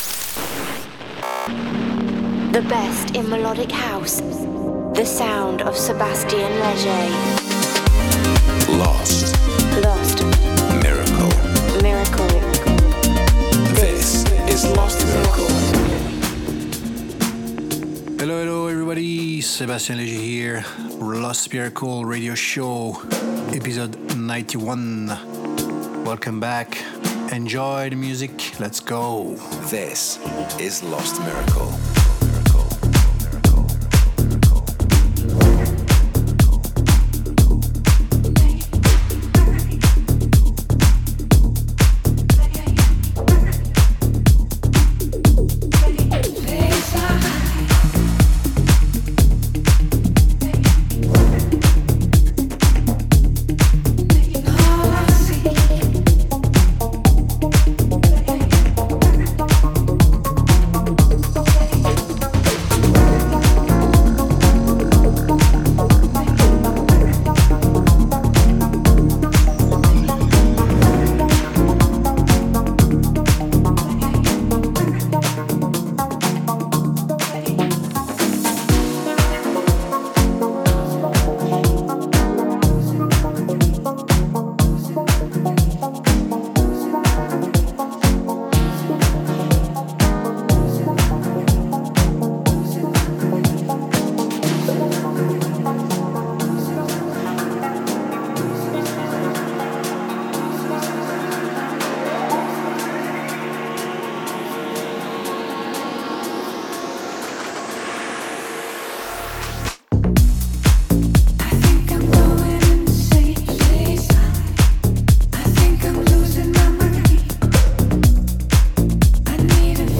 the monthly radio show